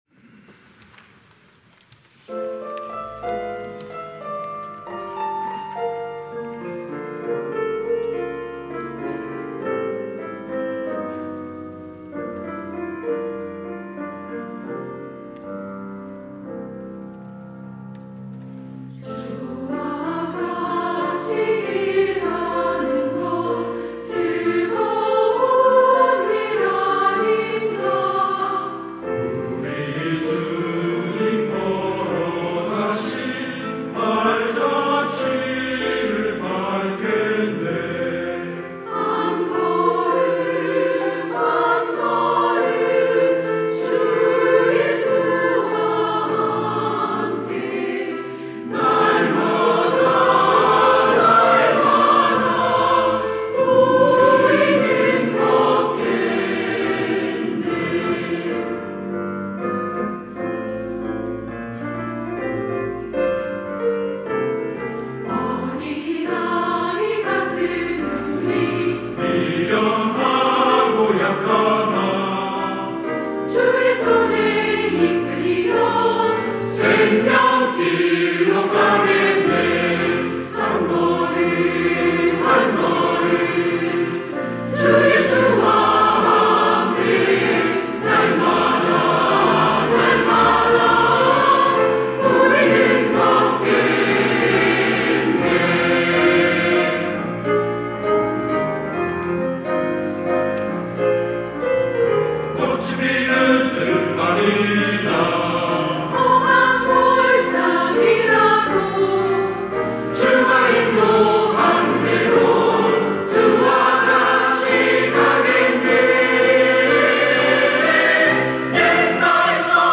시온(주일1부) - 주와 같이 길 가는 것
찬양대 시온